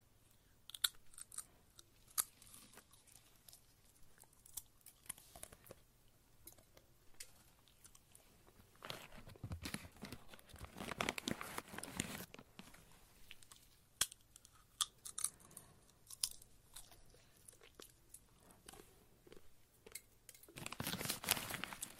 На этой странице собраны натуральные звуки семечек: от раскалывания скорлупы зубами до шуршания шелухи.
Хруст семечек подсолнуха в зубах